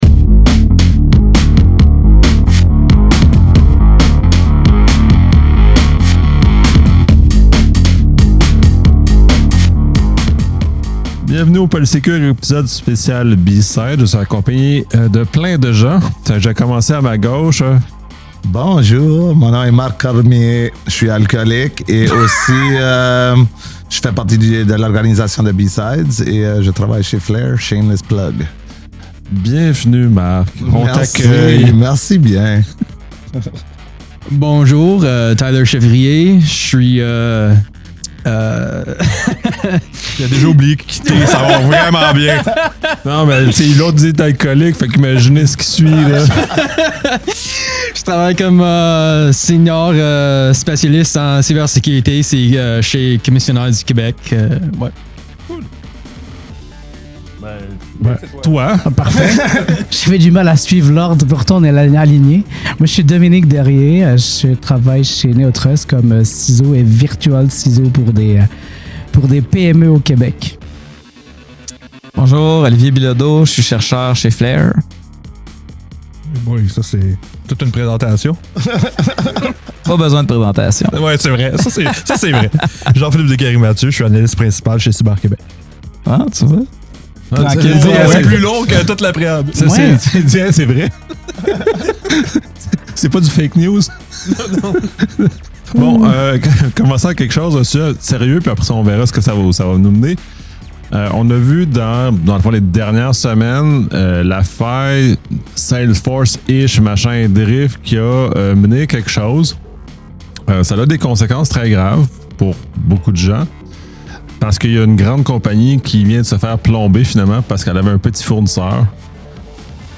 Ce panel enregistré lors du BSides Montréal 2025 réunit plusieurs experts en cybersécurité pour discuter des défis actuels de l’industrie, notamment la gestion des incidents, la protection des PME et l’évolution rapide des menaces.